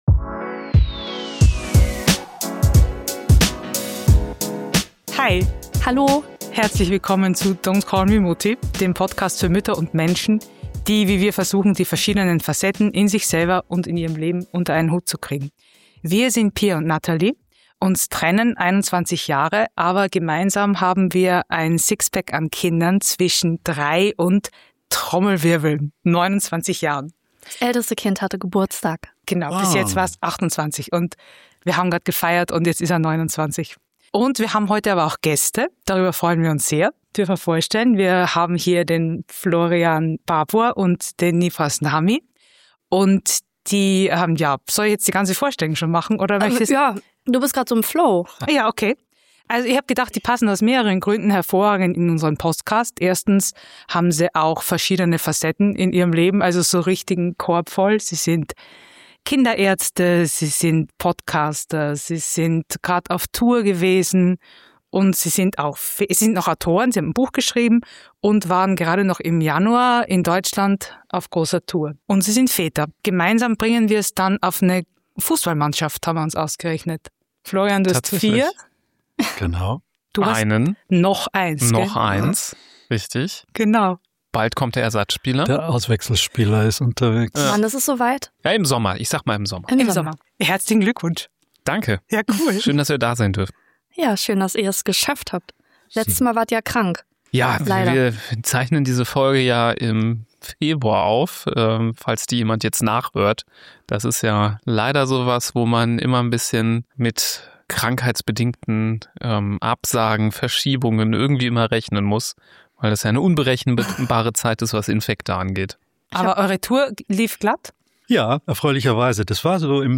Beschreibung vor 1 Monat Zwei Mütter, zwei Kinderärzte, eine Fußballmannschaft an Kids – und ein Thema, das in Familien gefühlt jeden Tag aufploppt: Essen.